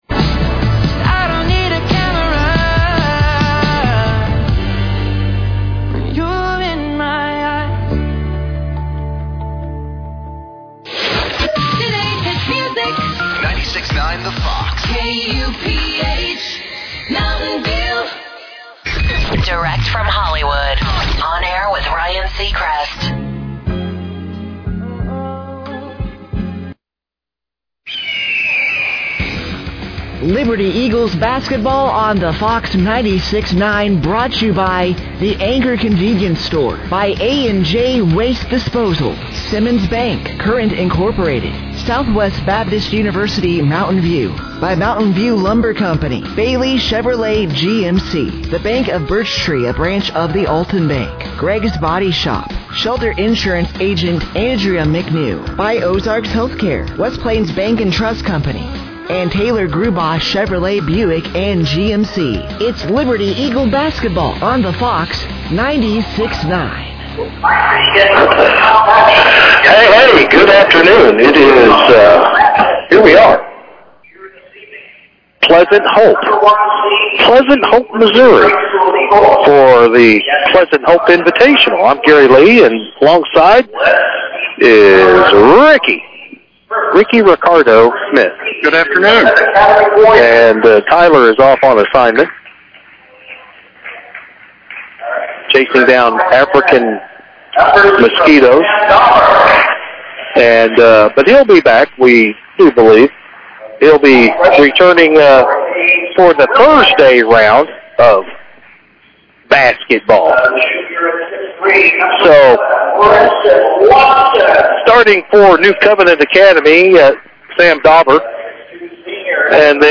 Game Audio Below:
Liberty-Eagles-vs.-New-Covenant-Warrors-Pleasant-Hope-Invitational-Game-One-1-13-26.mp3